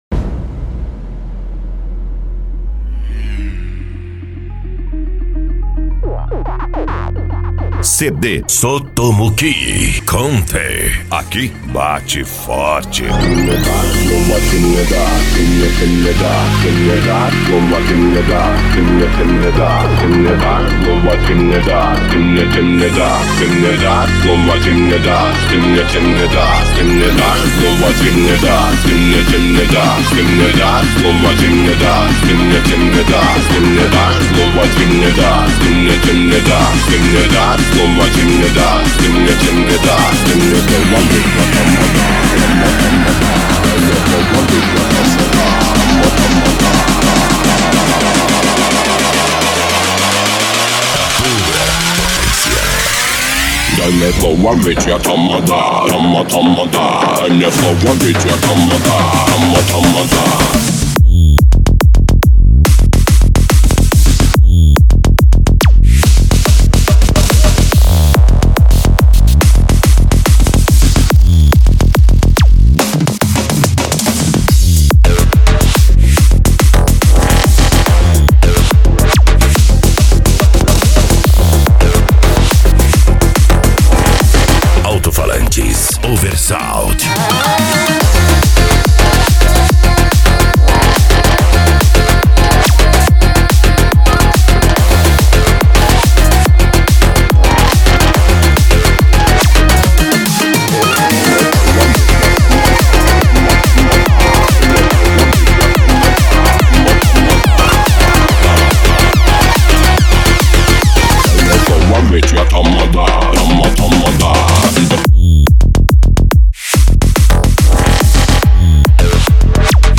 japan music